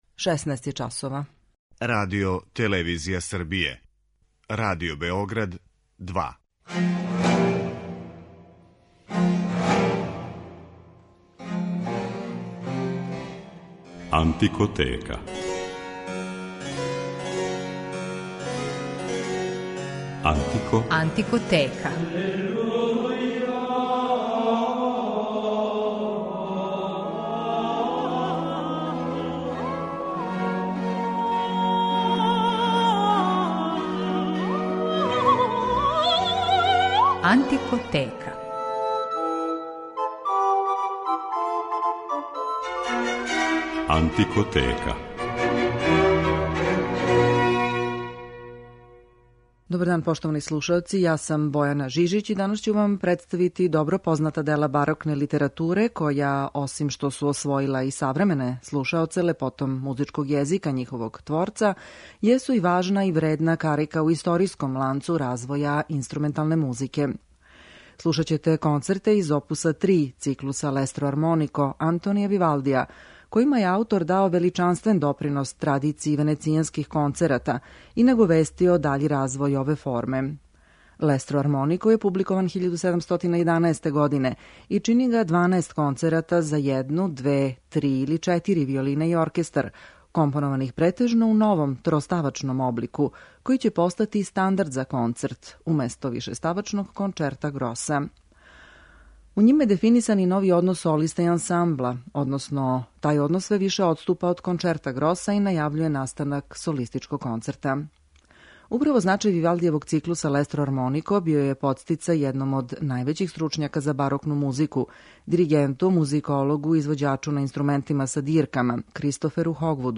И ове недеље водимо вас у свет ране музике